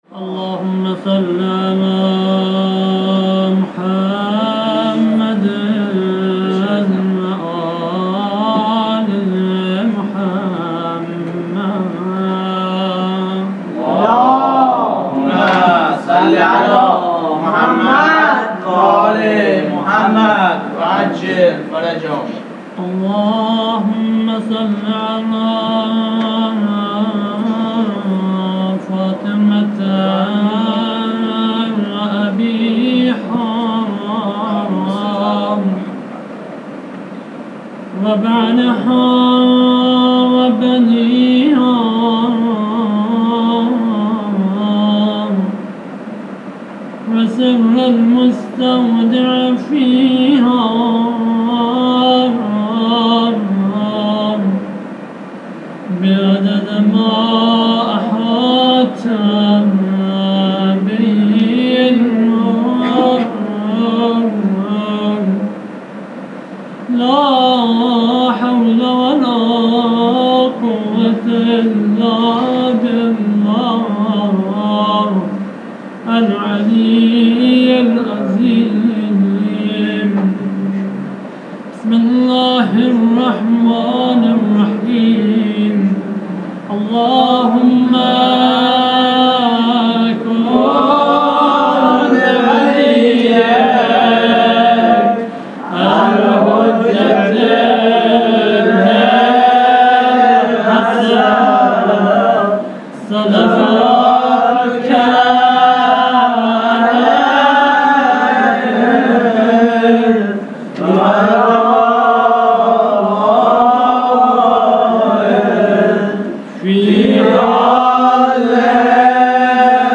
مناجات با امام زمان متاسفانه مرورگر شما، قابیلت پخش فایل های صوتی تصویری را در قالب HTML5 دارا نمی باشد.
مداح اهل بیت